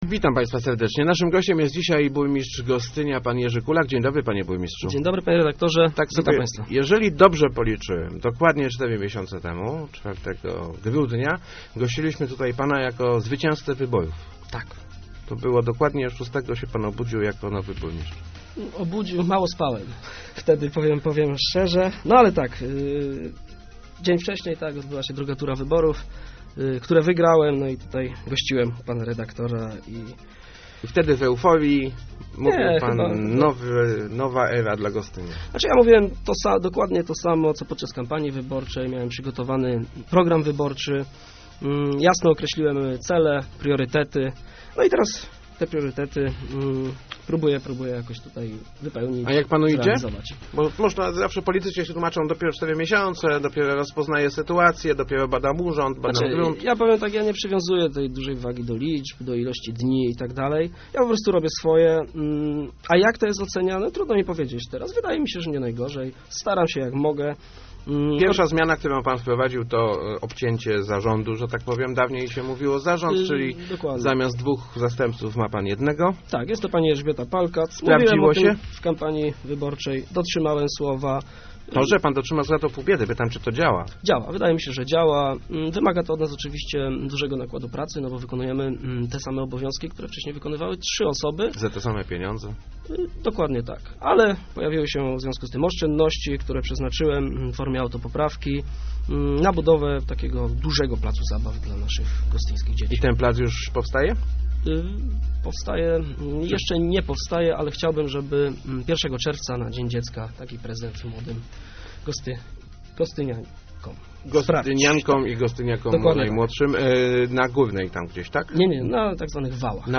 Gwiazdą tegrocznego Dnia Gostynia będzie Doda - powiedział w Rozmowach Elki burmistrz Gostynia Jerzy Kulak. Uwieńczy ona zmagania Pucharu Europy Strongmanów.